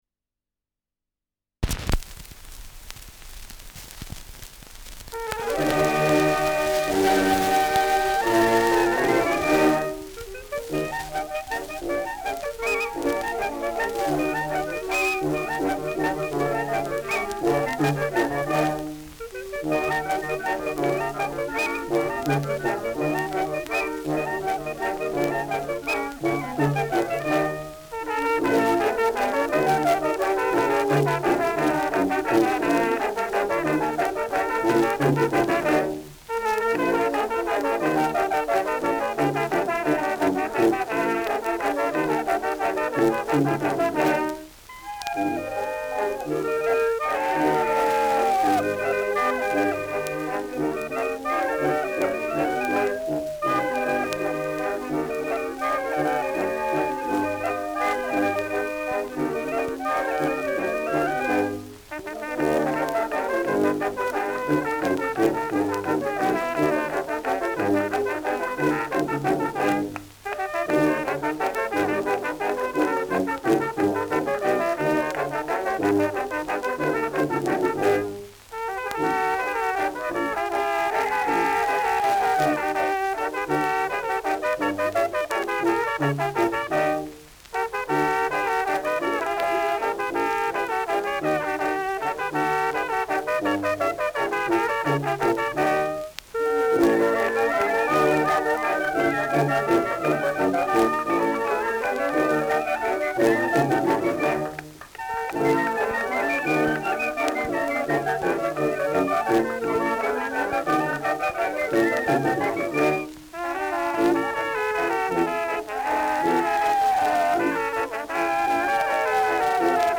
Schellackplatte
Stärkeres Grundrauschen : Leicht verzerrt an lauteren Stellen : Gelegentlich leichtes Knacken